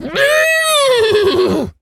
pgs/Assets/Audio/Animal_Impersonations/horse_neigh_05.wav at master
horse_neigh_05.wav